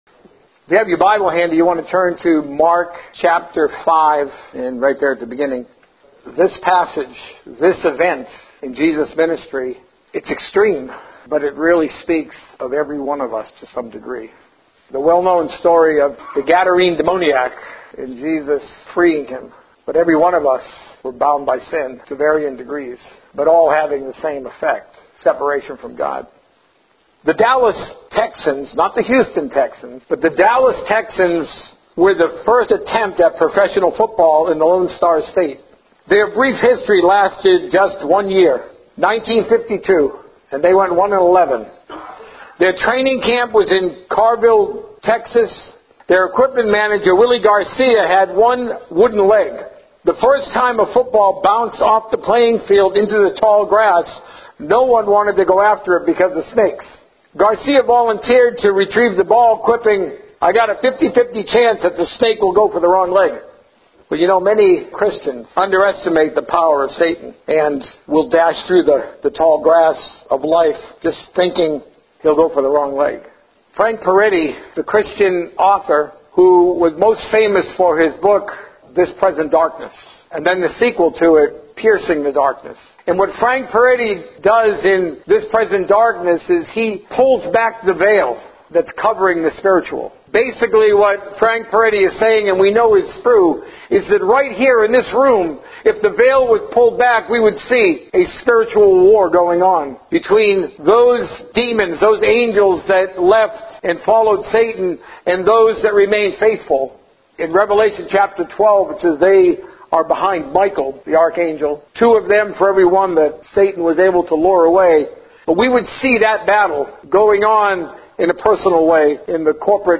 A message from the series "Time with Jesus." Trust God to match His resources to you need.